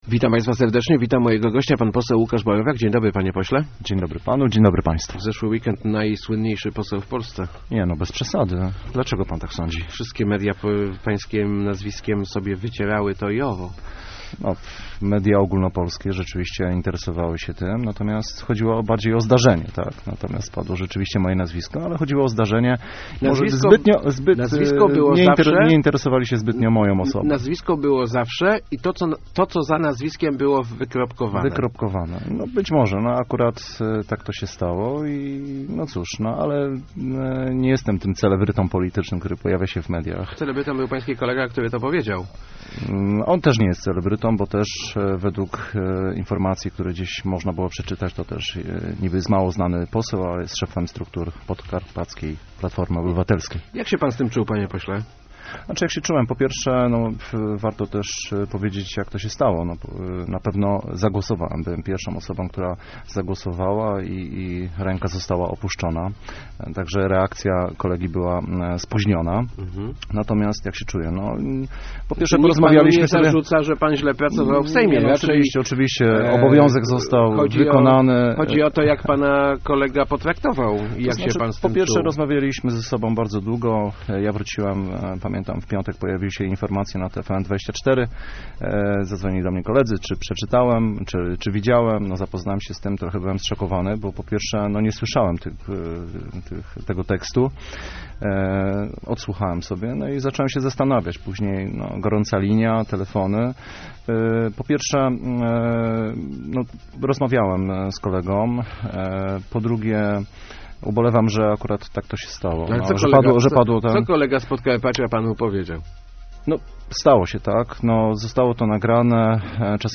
Brzydkie słowa w Sejmie padają często, ważne, że kolega mnie przeprosił - mówił w Rozmowach Elki poseł Łukasz Borowiak.